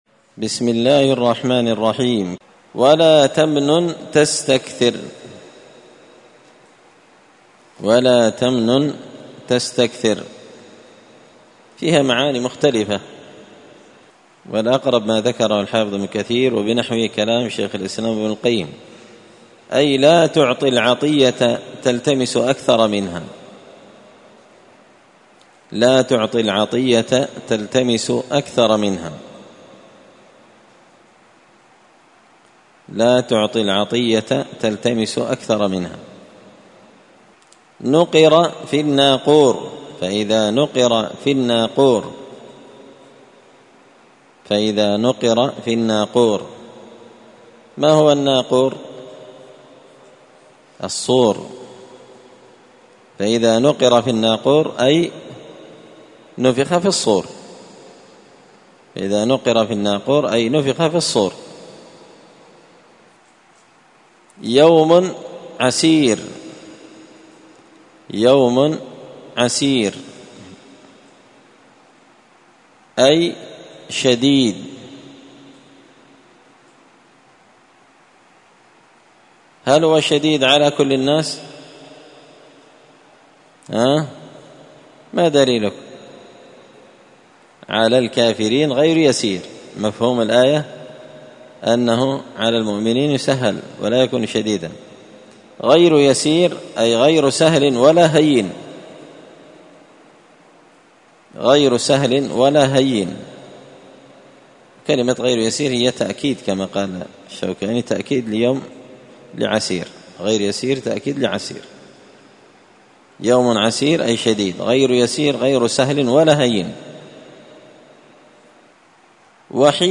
زبدة الأقوال في غريب كلام المتعال الدرس الخامس والتسعون (95)